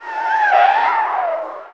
tyre_skid_04.wav